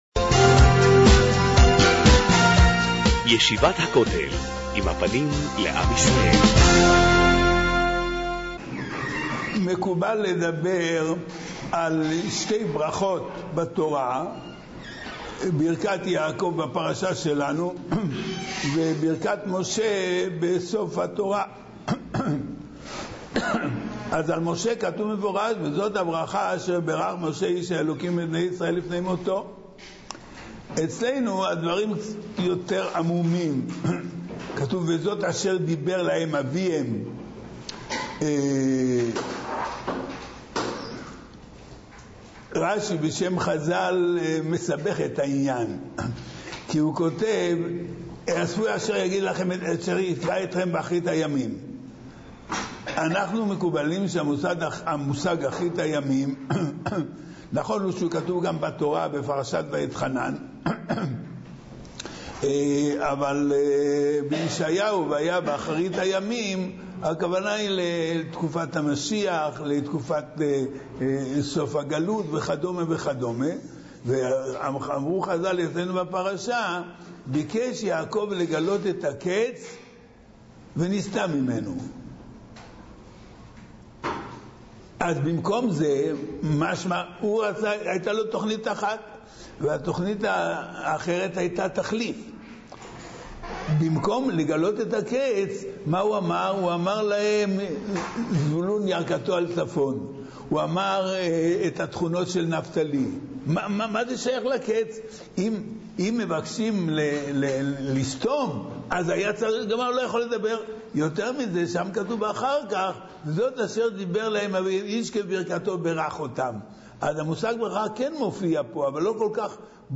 שיחה לפרשת ויחי - ישיבת הכותל